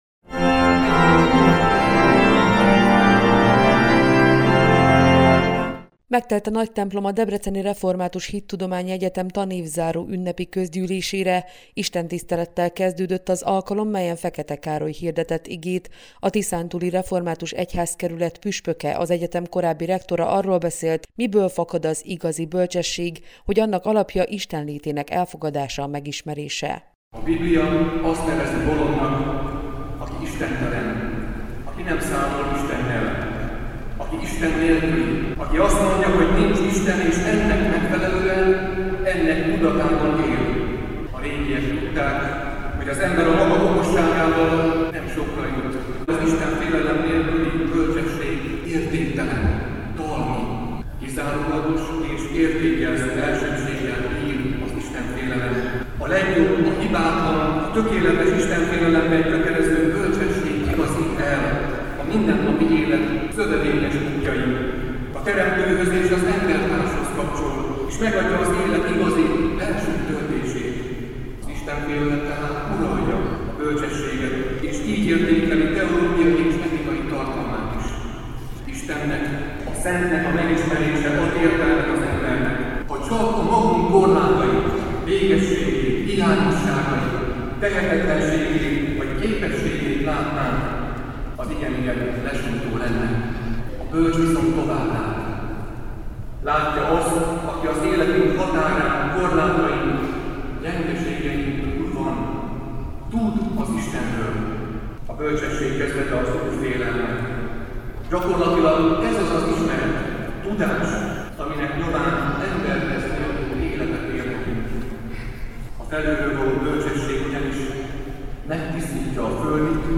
A tanévzáró ünnepi közgyűlésen Fekete Károly hirdetett igét. A Tiszántúli Református Egyházkerület püspöke prédikációjában kiemelte, hogy az ember a maga okosságával nem sokra jut.
A 2014-2015-ös tanév zárásán Bölcskei Gusztáv, a Debreceni Református Hittudományi Egyetem rektora köszöntötte az egybegyűlteket.
drhe-tanevzaro-vagott.mp3